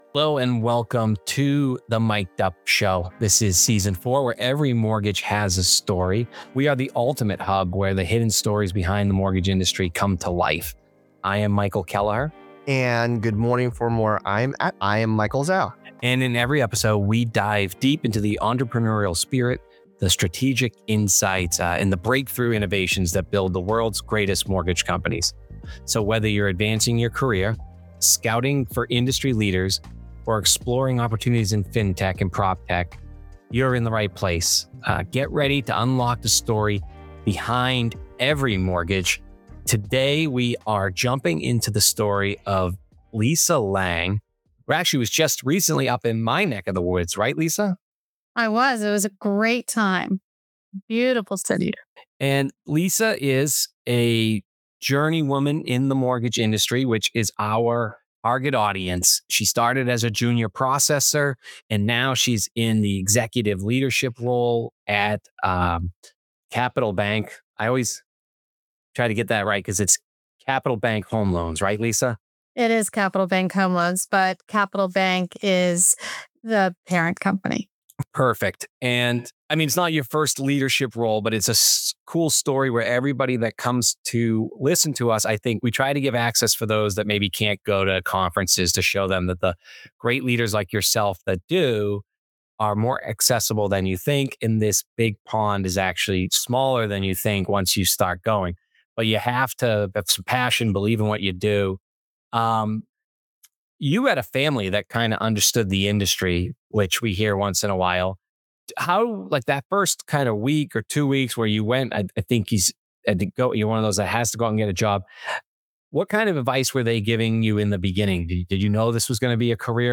In this explosive conversation, Rob opens up about his years as a U.S. Marine, his experience on 9/11, and the reality of America’s forever wars.